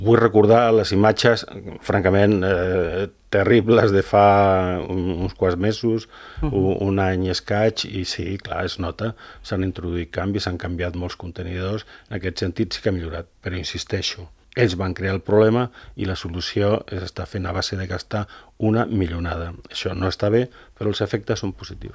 Ho ha dit a l’entrevista política de Ràdio Calella TV: